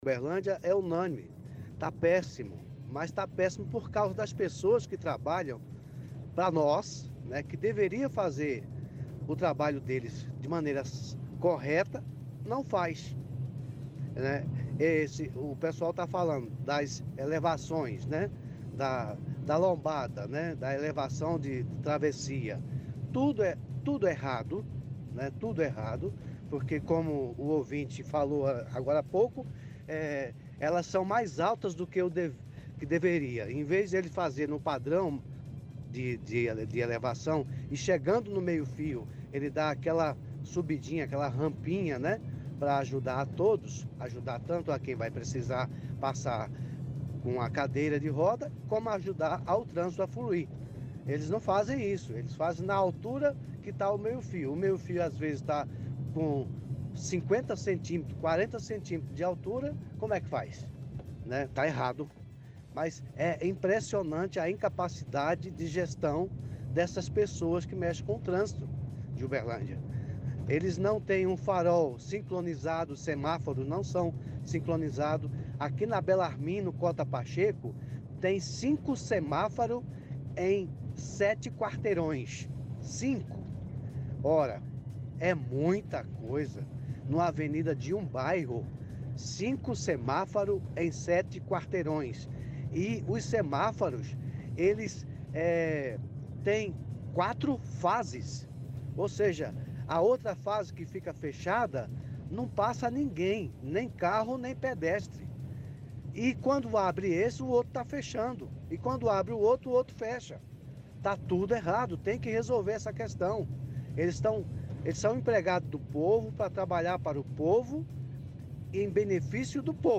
– Ouvinte reclama das travessias nas ruas da cidade.